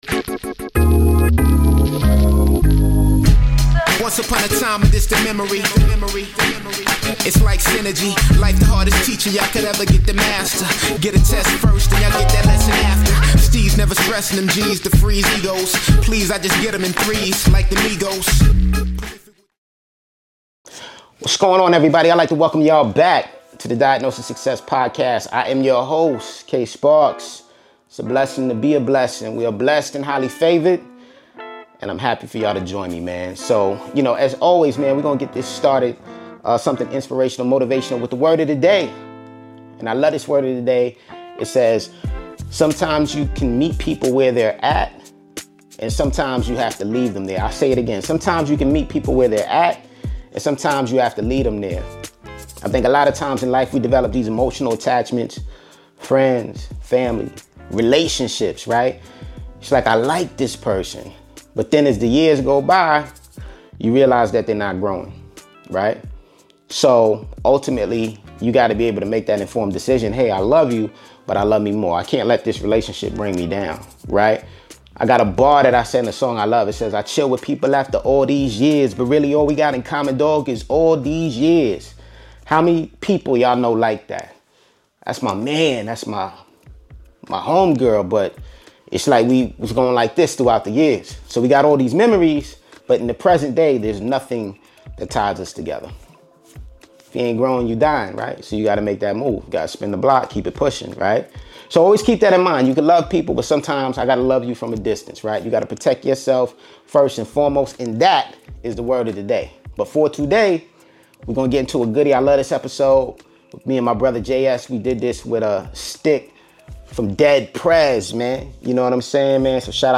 During this interview Stic discusses how he became started in music, the beginning stages of Dead Prez, creation of their classic records, Malcom X, power of community, Jay-Z collaboration, his new book entitled The 5 Principles, and much more.